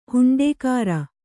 ♪ huṇḍēkāra